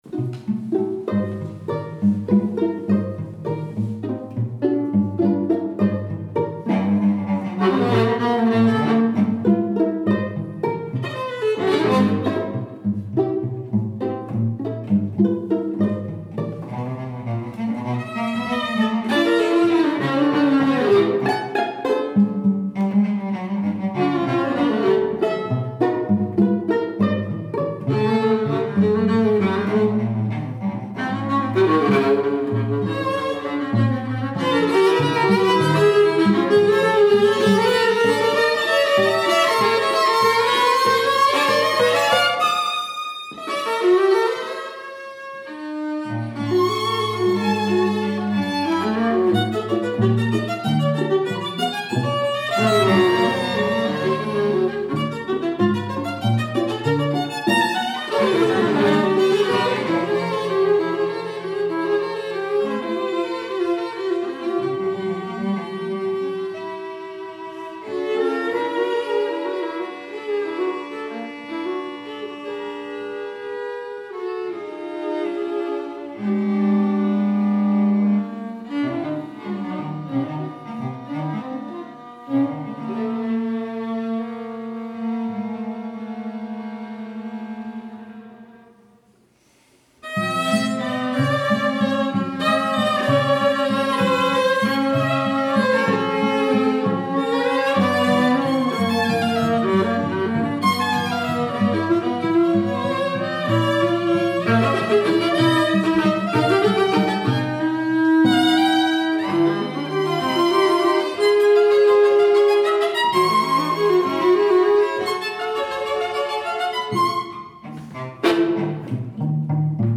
Streichtrios